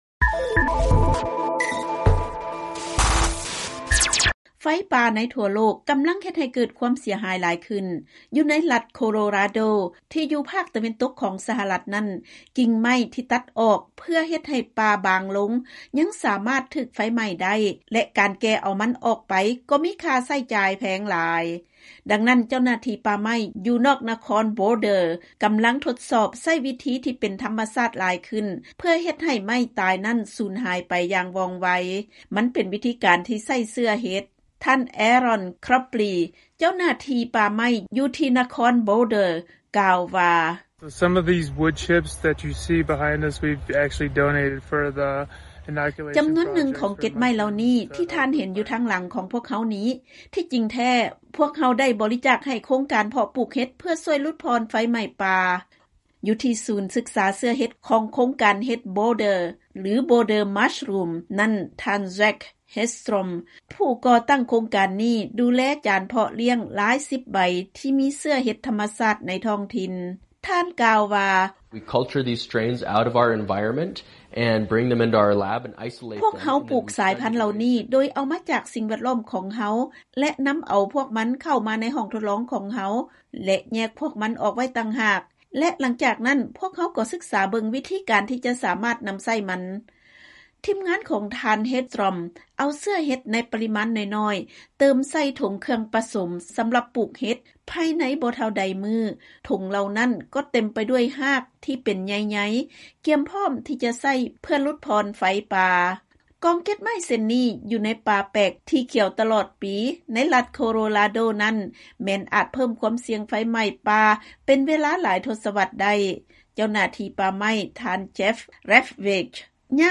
ເຊີນຮັບຟັງລາຍງານ ກ່ຽວກັບ ເຊື້ອເຫັດ ສາມາດຫຼຸດຜ່ອນຄວາມສ່ຽງໃນການເກີດໄຟໄໝ້ປ່າ ໄດ້